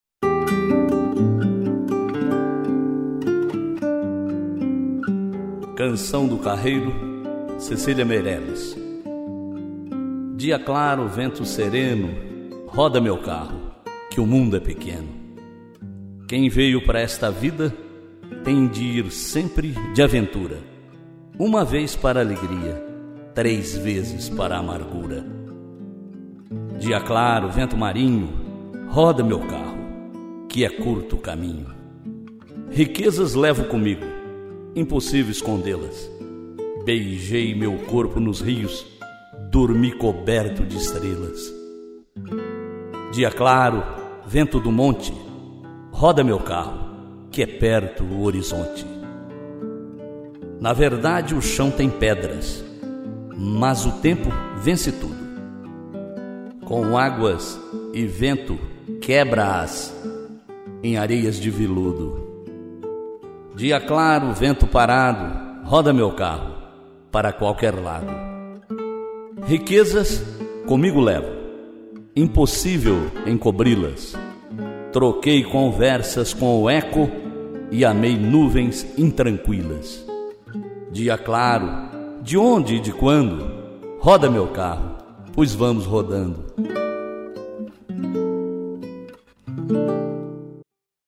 declamação